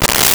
Train Horn Blasts 01
Train Horn Blasts 01.wav